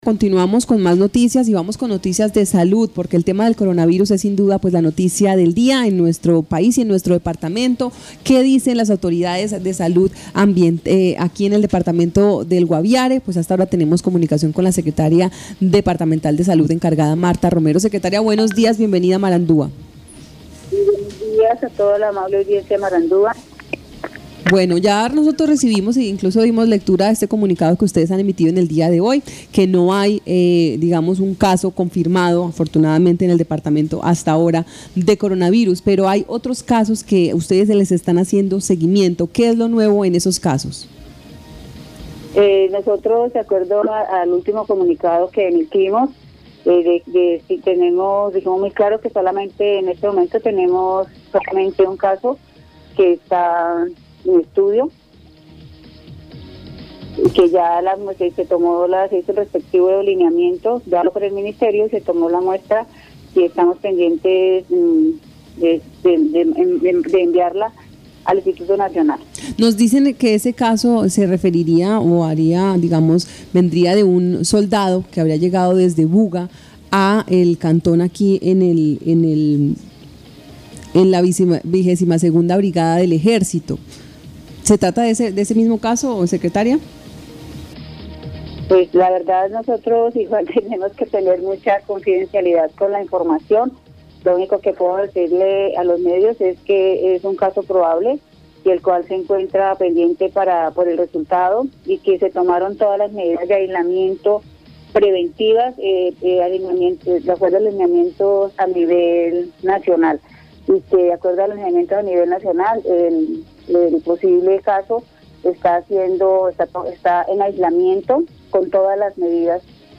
Escuche a Martha Romero, secretaria (e) de Salud del Guaviare.